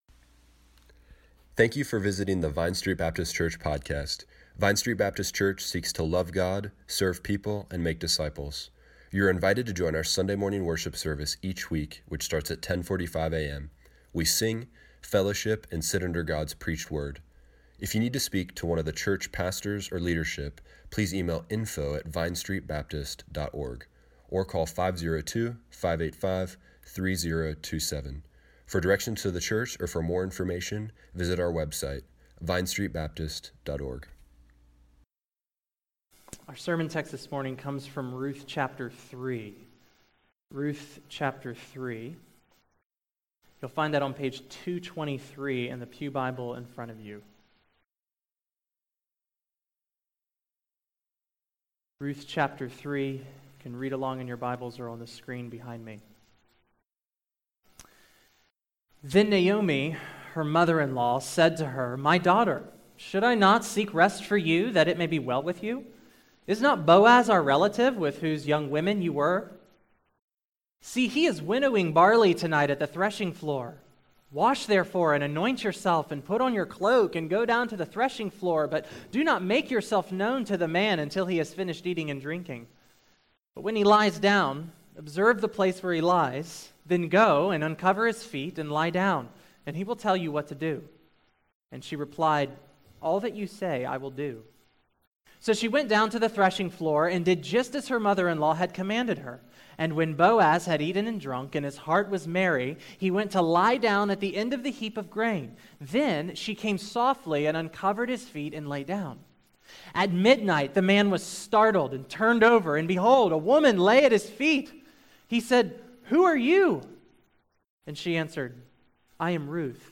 February 25, 2018 Morning Worship | Vine Street Baptist Church